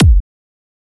edm-kick-39.wav